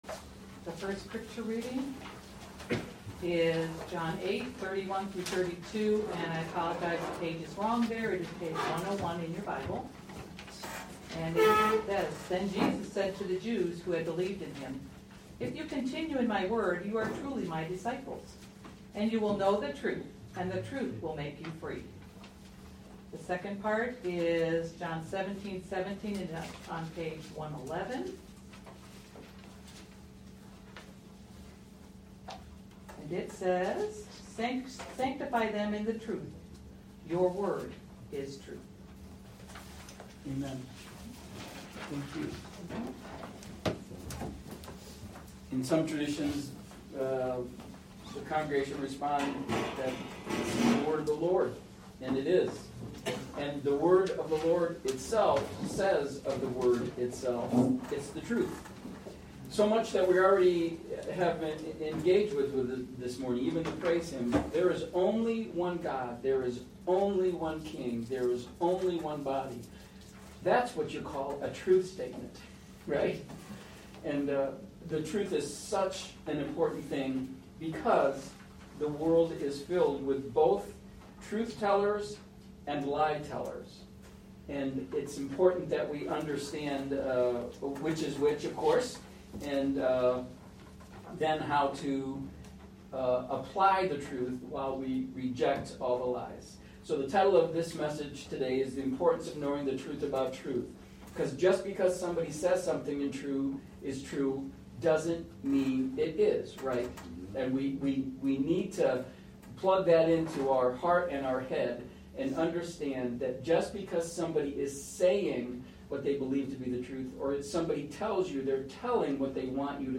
Feb.-16-Service.mp3